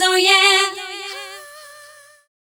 Eko Oh Yeh 139-G.wav